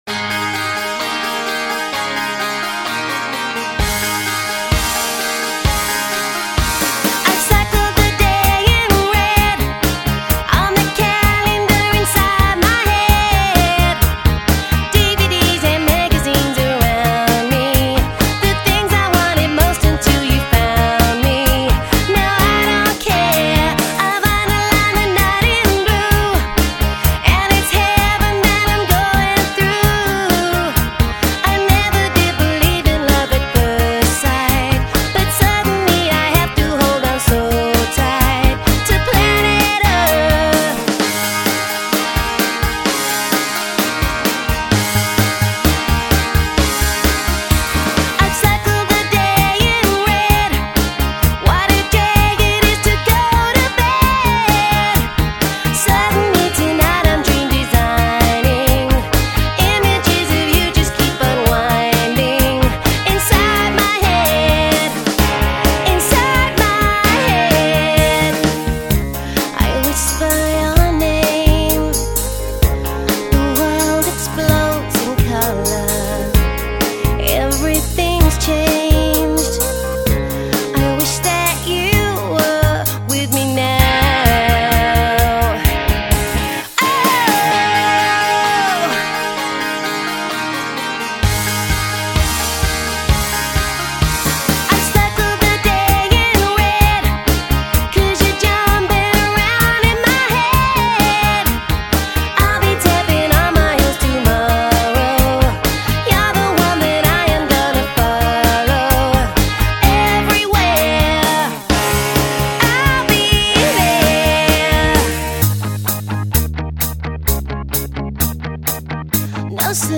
Up Tempo Fm vx/guitars/drums/keys